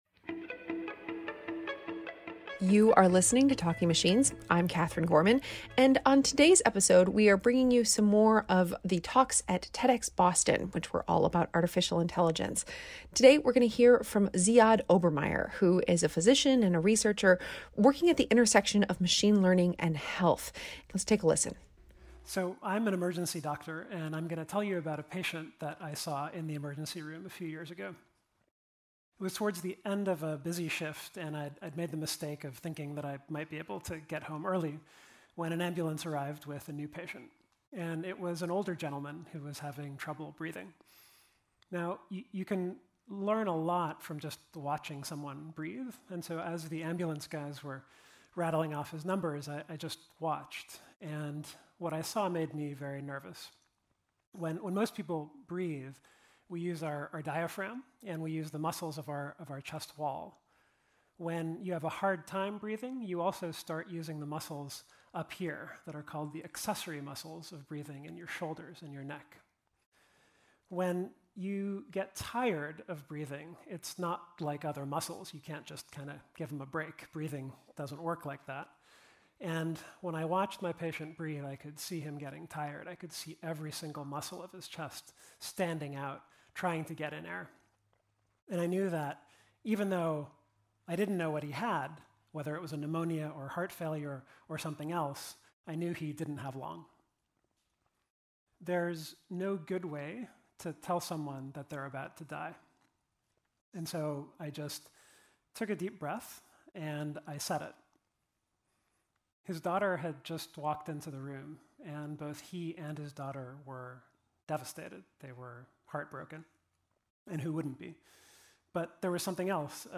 talk from TedX Boston